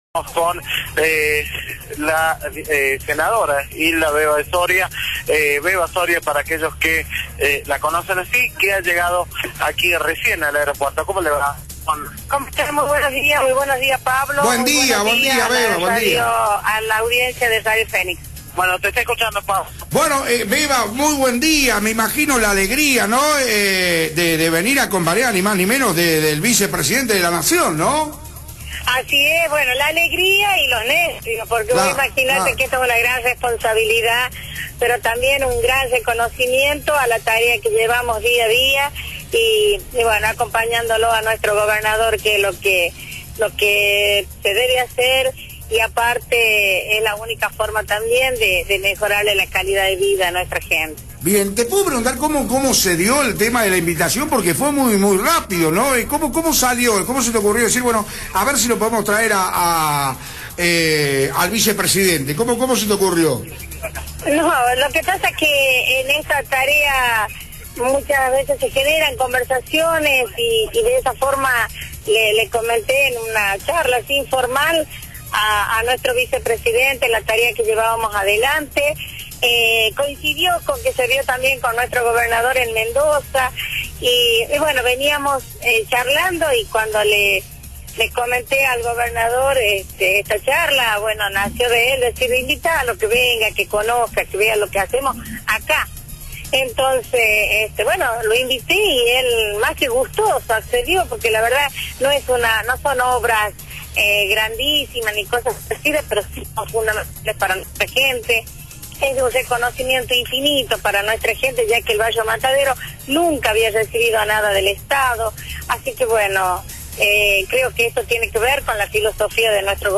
Hilda Aguirre de Soria, senadora nacional, por Radio Fénix  Carlos Crovara, titular de Vivienda, por Radio Fénix
hilda-aguirre-de-soria-senadora-nacional-por-radio-fc3a9nix.mp3